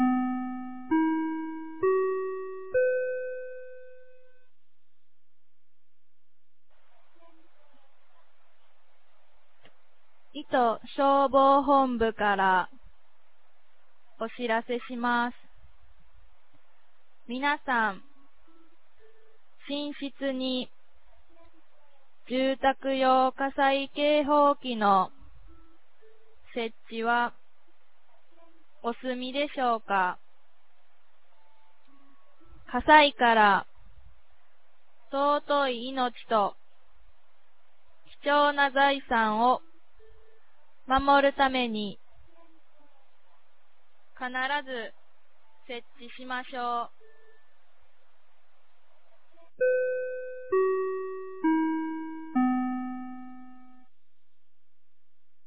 2025年10月06日 10時01分に、九度山町より全地区へ放送がありました。